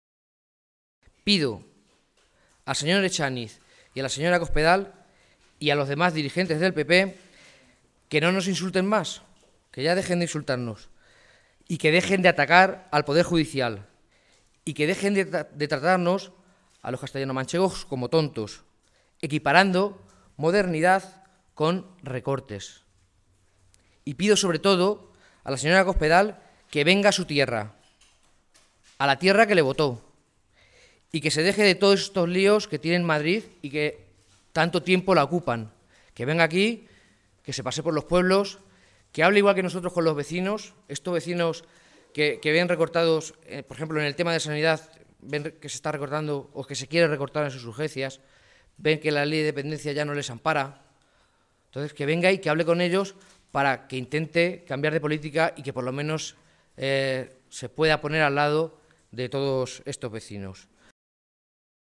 Jesús Fernández Clemente, alcalde de Tembleque
Cortes de audio de la rueda de prensa